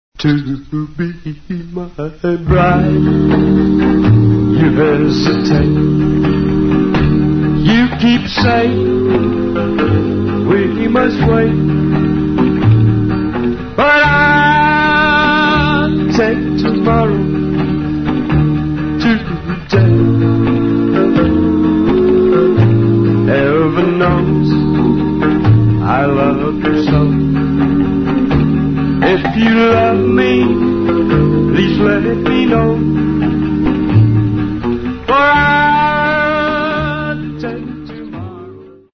Talents : Vocals, Guitar